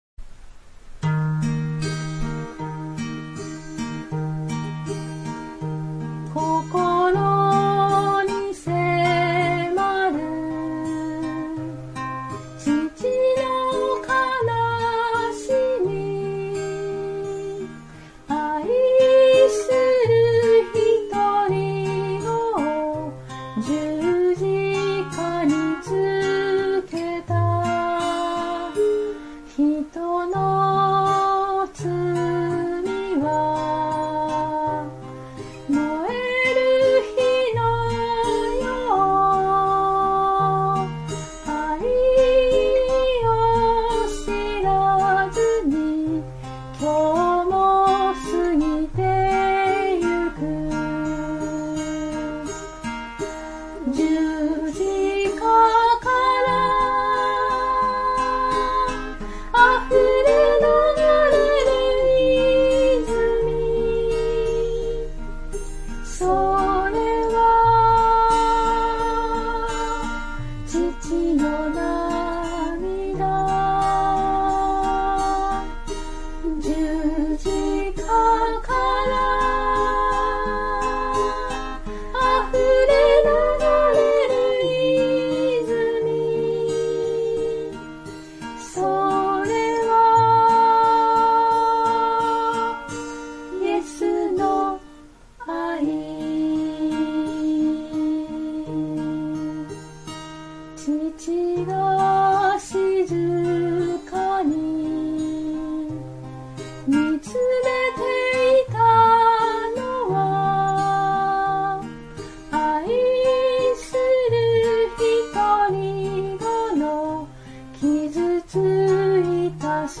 （徳島聖書キリスト集会集会員）